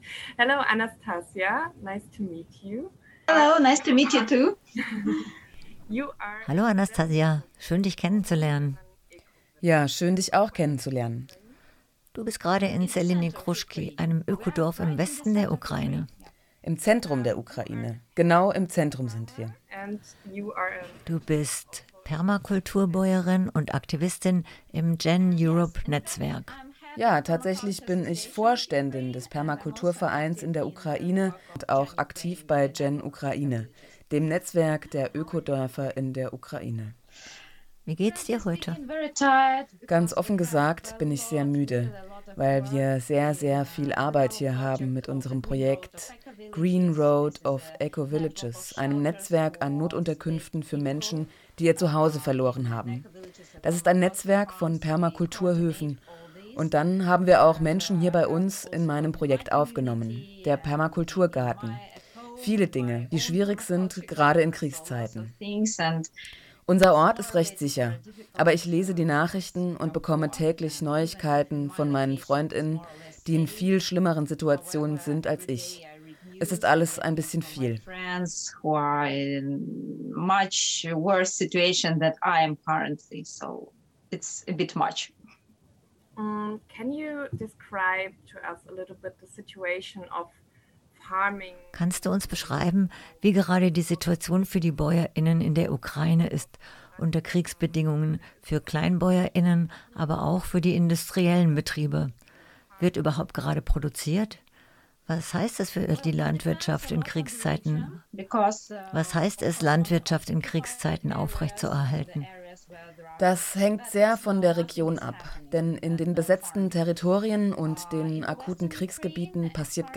Im Interview ist eine Kleinbäuerin aus der Zentral Ukraine